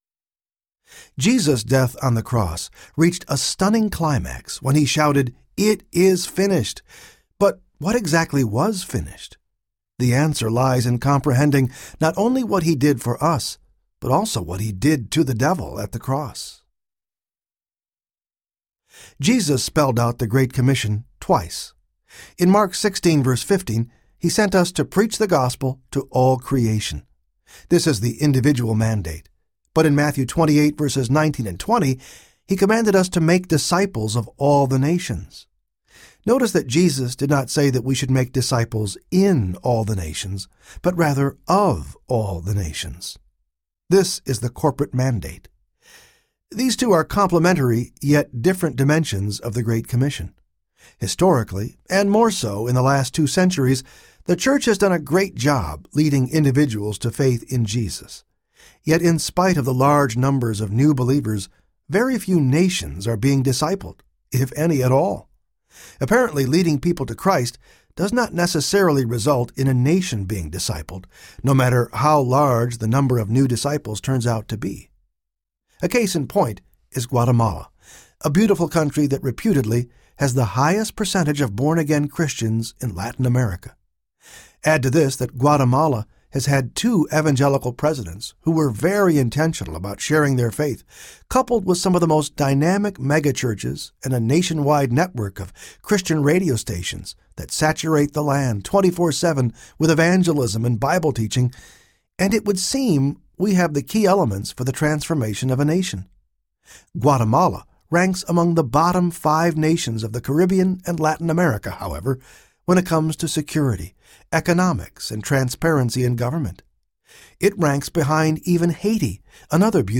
Ekklesia Audiobook
Narrator
9.12 Hrs. – Unabridged